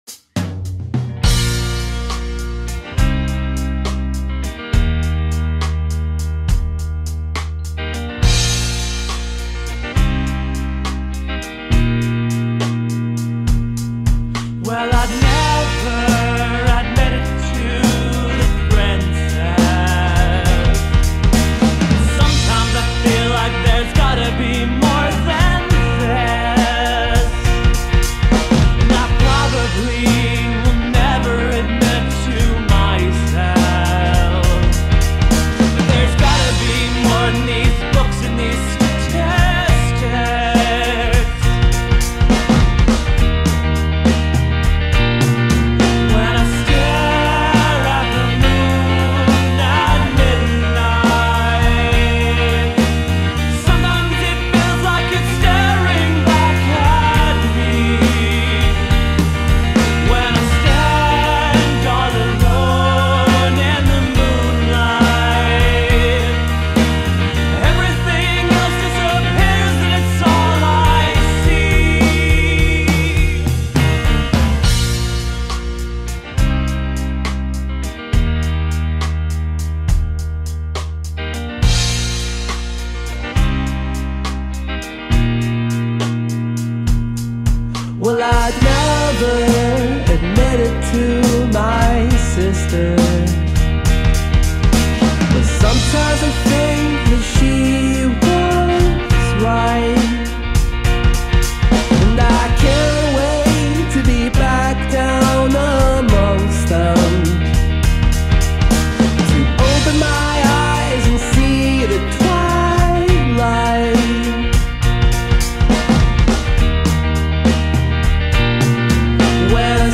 five super fun and rockin' songs
pop-punk